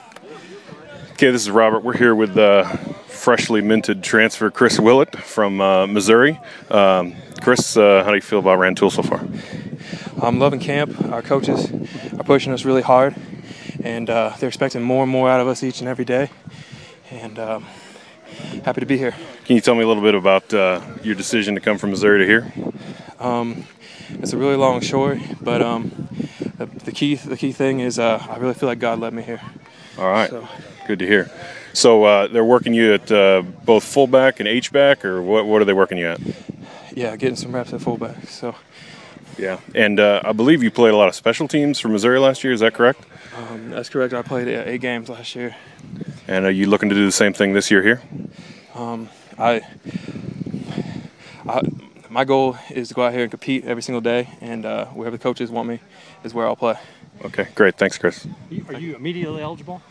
Post Practice Interview